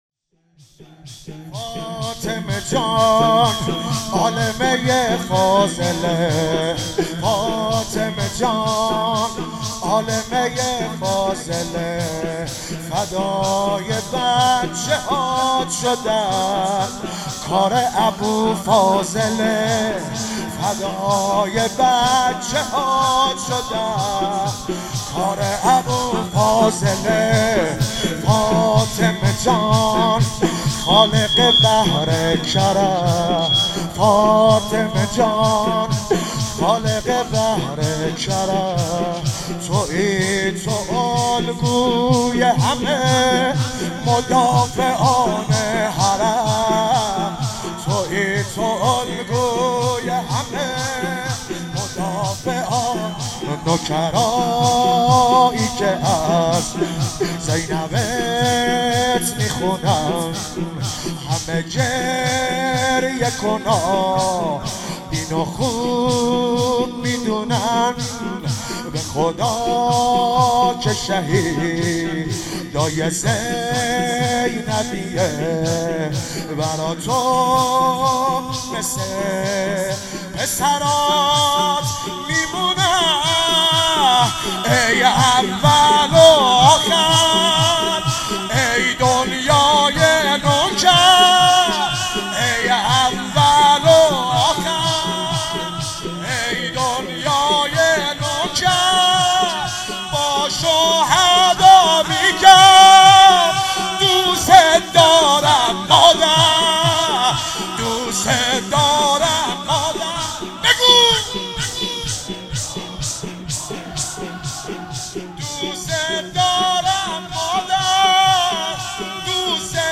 فاطمیه 95 - شور - فاطمه جان عالمه فاضله